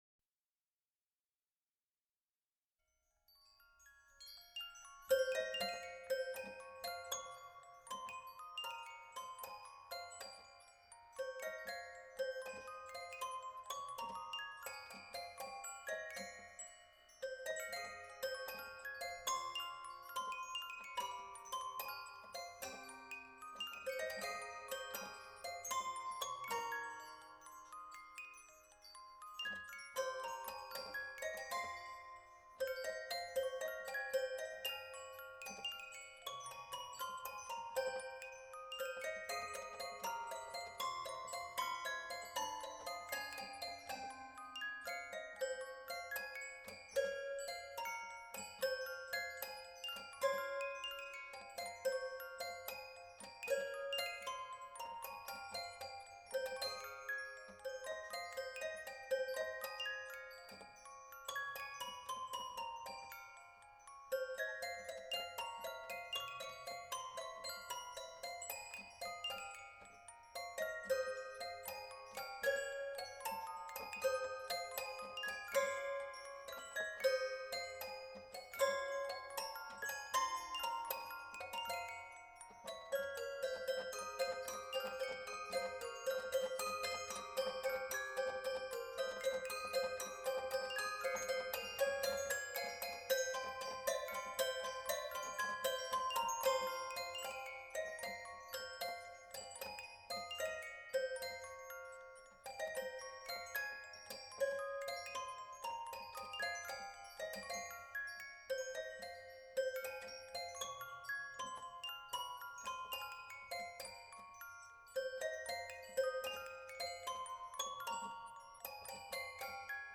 toy piano, wind chimes, music box